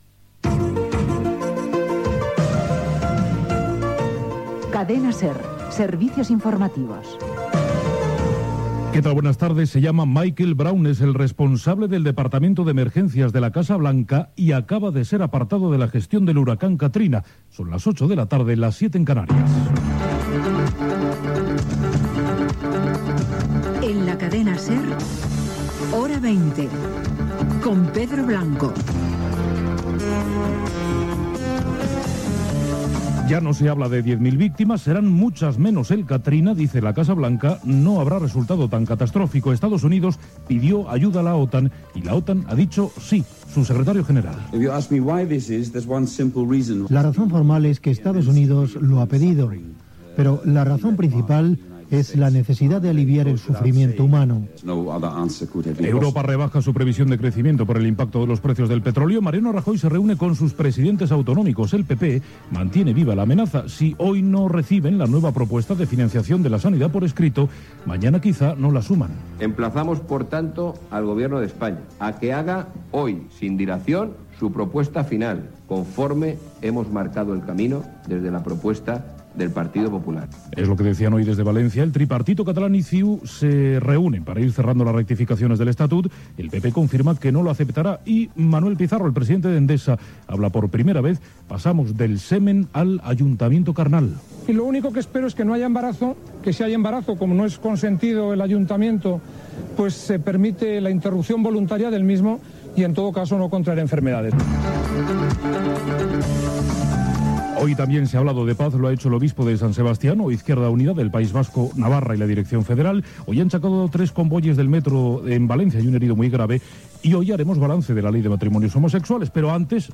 Careta del programa, hora, huracà Catarina, titulars, pronòstic del temps, pluges fortes a Catalunya, esports, avenç Hora 25, hora.
Informatiu